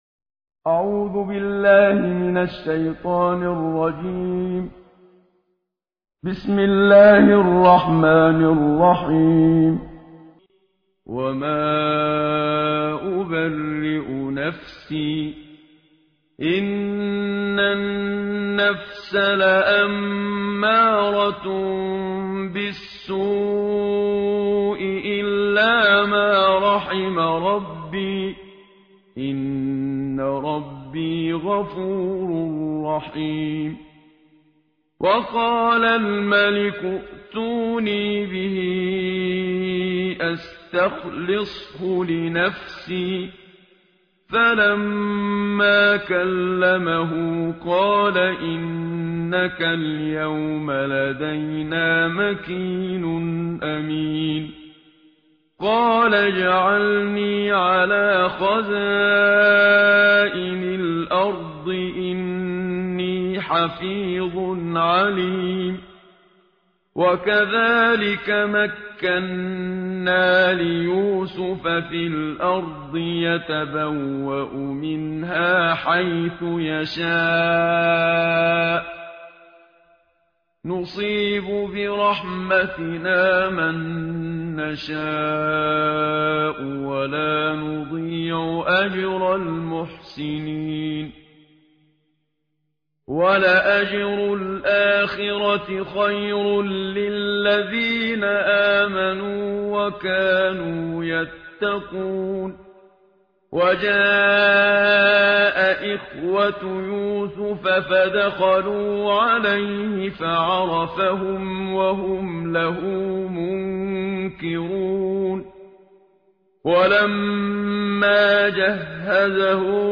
به گزارش نوید شاهد همدان ، ترتیل جزء ۱۳ قرآن کریم با صدای استاد منشاوی به پیشگاه مقدس هشت هزار شهید دوران دفاع مقدس استان همدان تقدیم می‌شود.